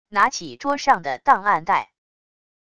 拿起桌上的档案袋wav音频